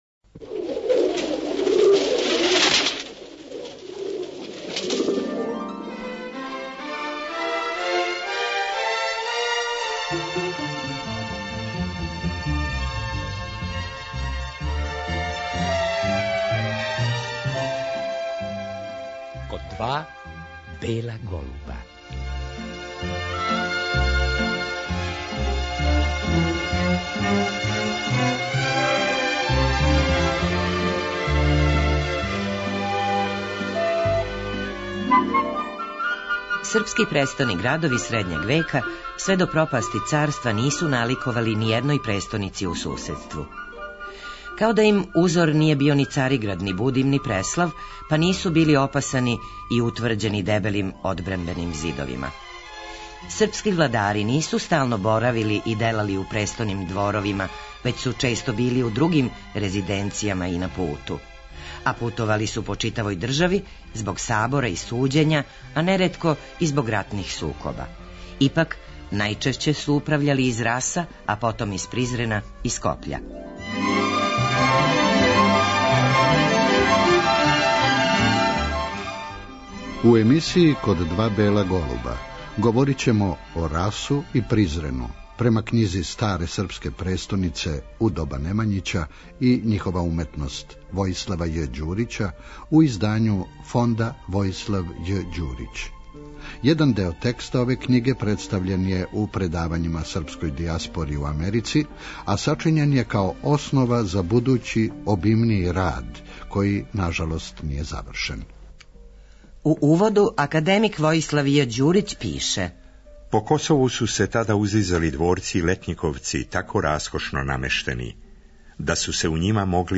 Како се ове године навршава 20 година од одласка уваженог универзитетског професора, преминуо је 12. маја 1996. године, у серијалу ових емисија слушаћемо и професорова сећања на почетке рада на истраживању наше историје уметности.